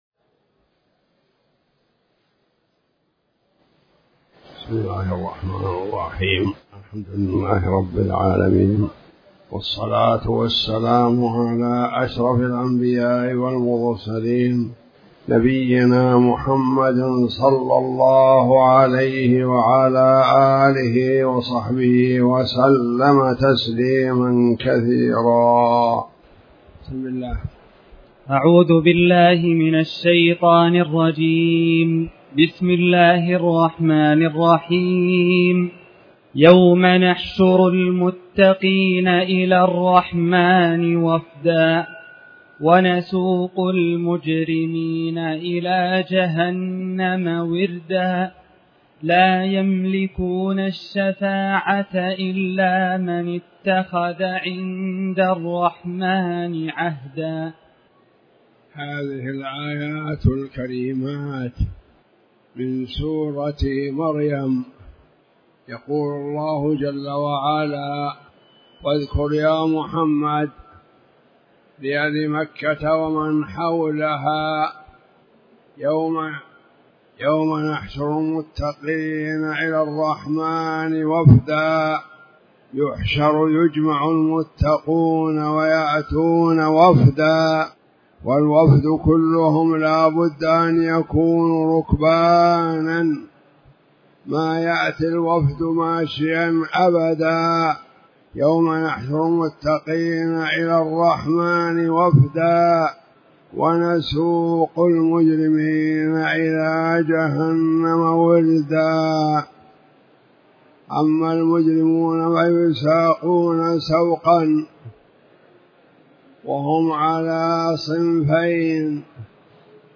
تاريخ النشر ٤ ذو الحجة ١٤٣٩ هـ المكان: المسجد الحرام الشيخ